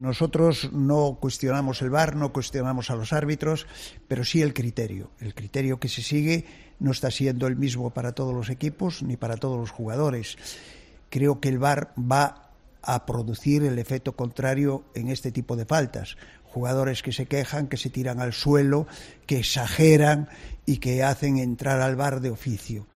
Declaraciones en exclusiva a COPE Vigo del presidente del Celta